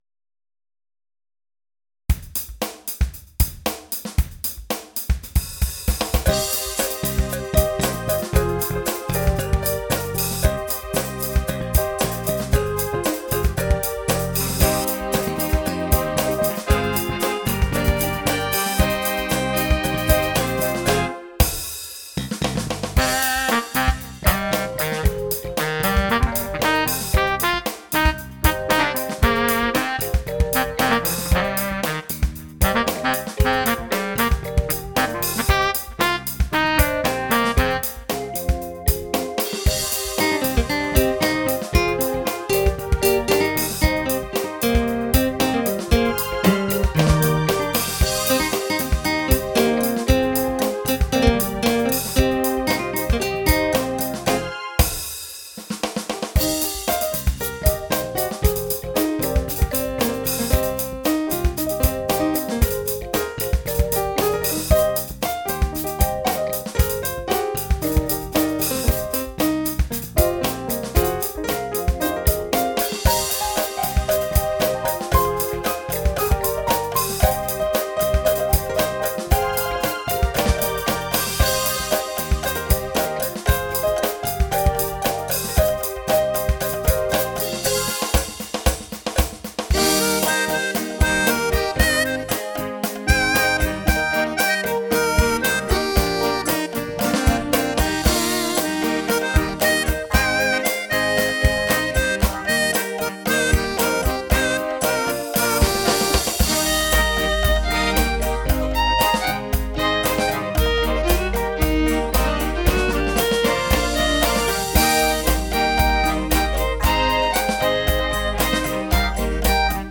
on a Roland FA-06 Music Workstation
Creation and Production done entirely on the workstation.